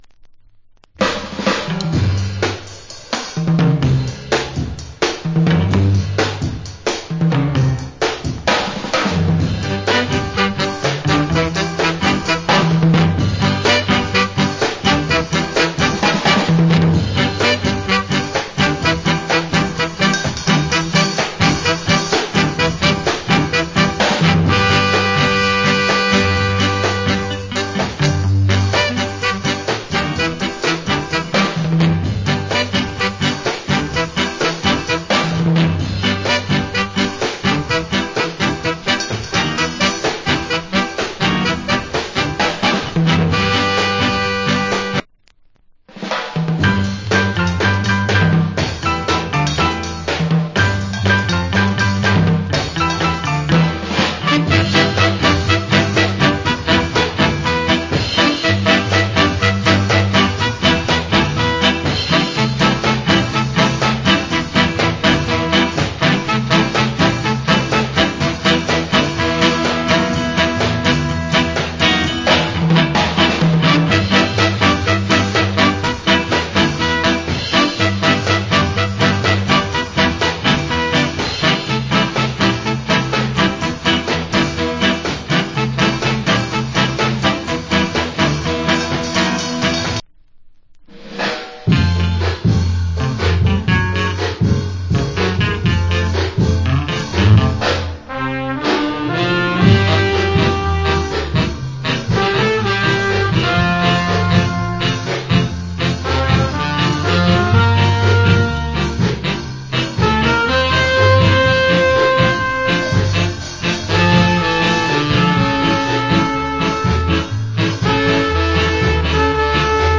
Great Ska Inst. 1988 Japan.